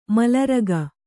♪ malar